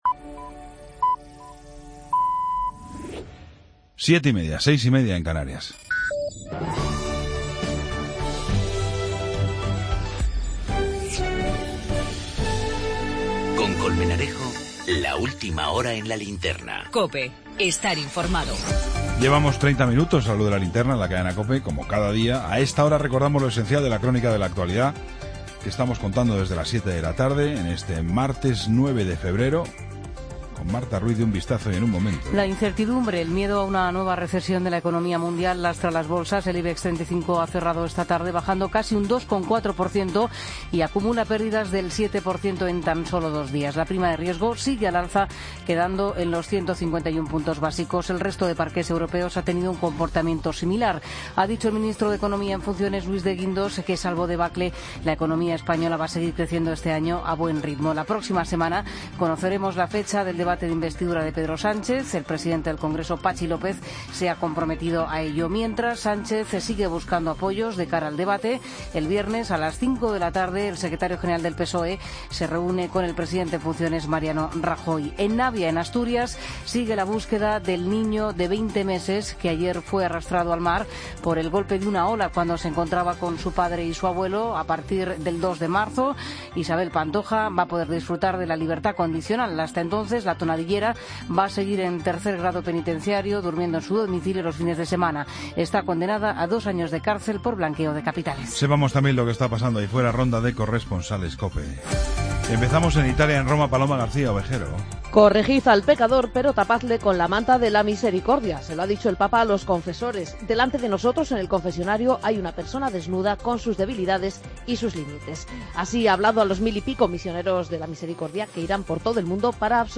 Ronda de corresponsales.